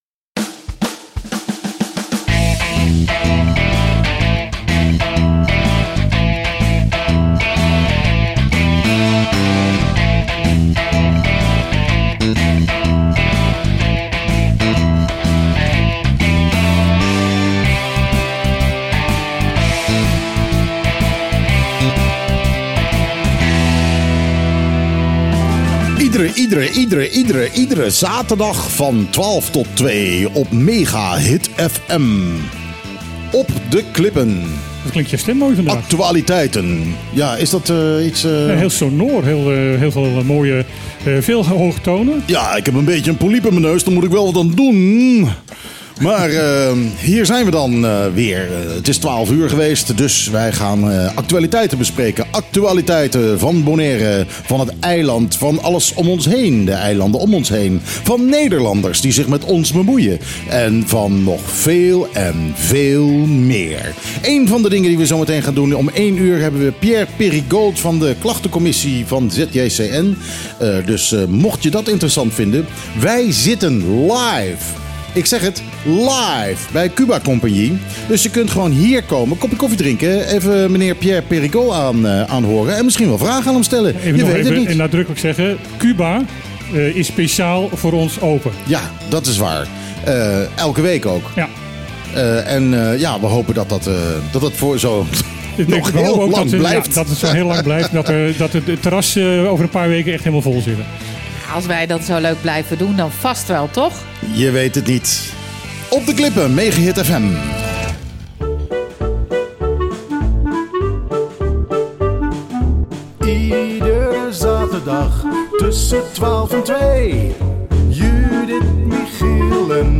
Hoeveel klachten krijgt de klachtencommissie van ZJCN eigenlijk? Bonaire’s enige Nederlandstalige actualiteitenradioshow Op De Klippen, elke zaterdag van 12:00 tot 14:00 live op Mega Hit 101.1 FM, was deze week op zijn zachtst gezegd belangwekkend te noemen.
Dat ging er verhit aan toe.